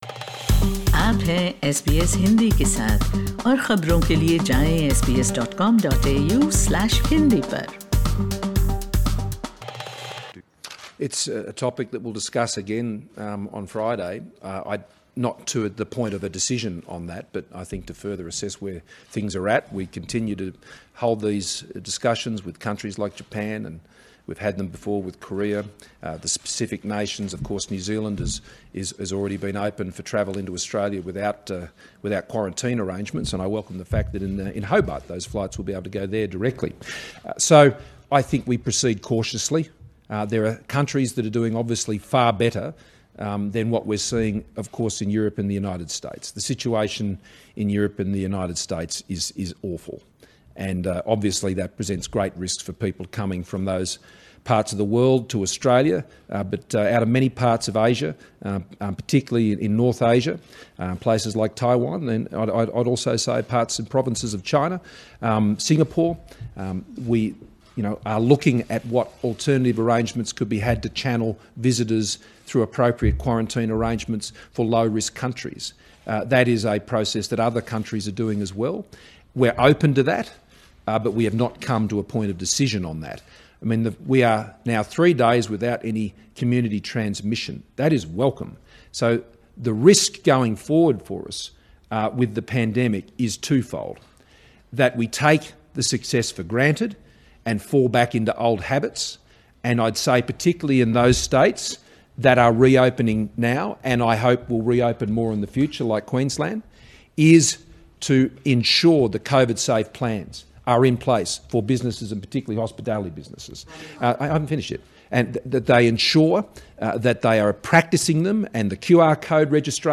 Prime Minister Scott Morrison speaks to the media during a press conference at Parliament House.